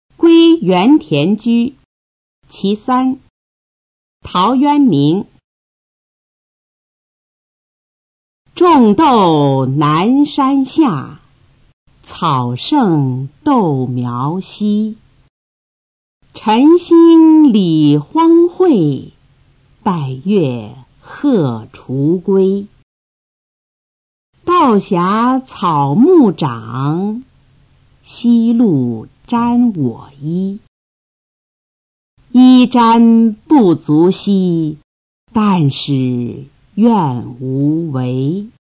《归园田居(其三)》原文与译文（含赏析、朗读）　/ 陶渊明